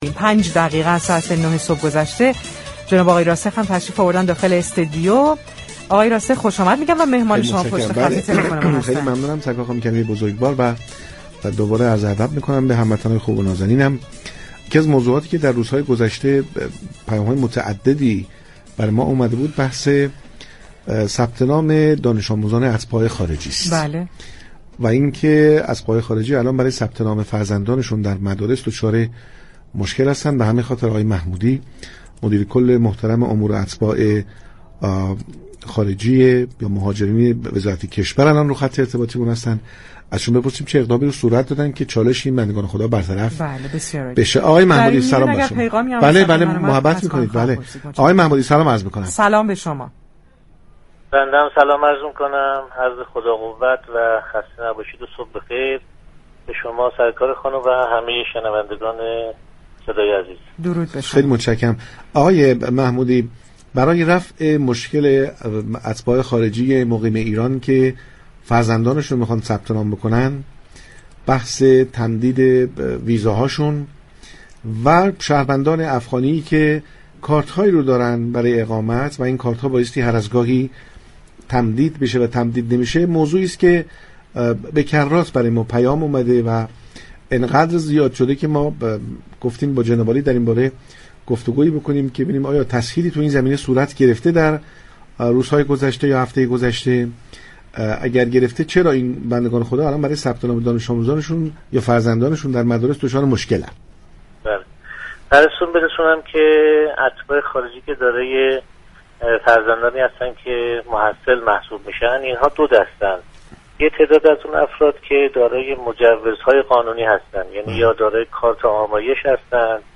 مهدی محمودی، مدیر كل امور اتباع خارجی وزارت كشور در گفتگو با برنامه پارك شهر شرایط حضور اتباع خارجی و ثبت نام دانش آموزان این خانواده ها در ایران را تشریح كرد.